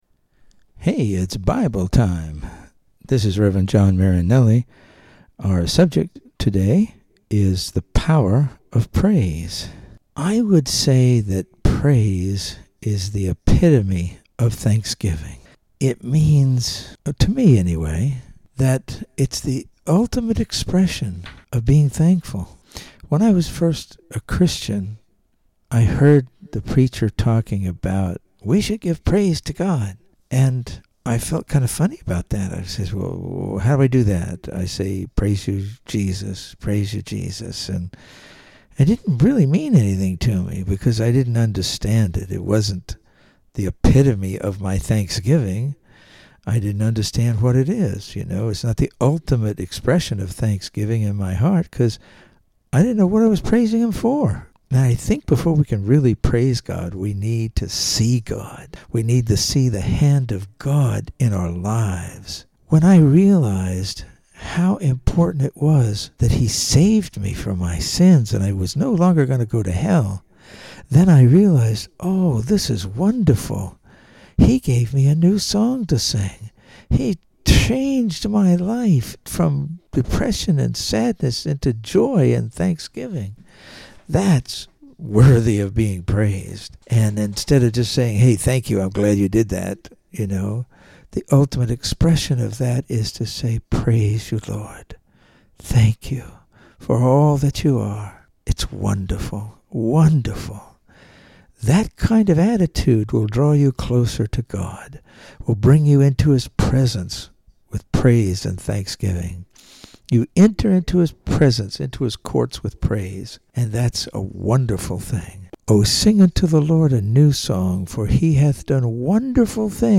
Welcome to Bible Time. The audio files are all 3-6 minute devotional messages that are meant to encourage and